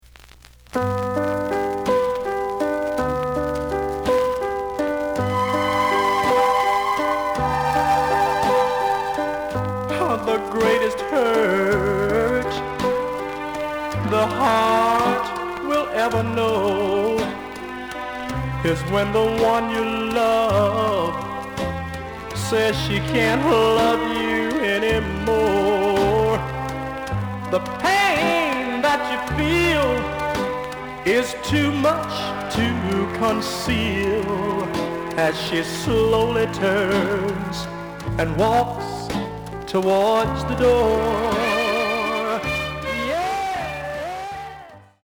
●Genre: Rhythm And Blues / Rock 'n' Roll